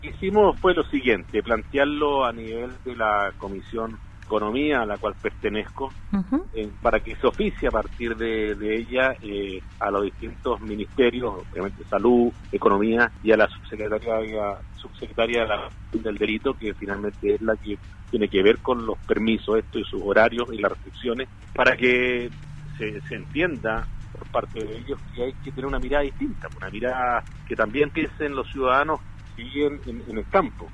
En entrevista con Radio Sago, el diputado del Distrito 25 Harry Jurgensen, dio a conocer la solicitud que realizó al nivel central para que se amplíen las horas de los permisos que otorga la Comisaría Virtual.